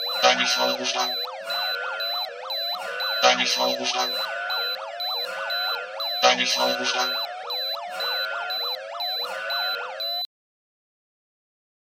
klingelton-frau-ruft-an.mp3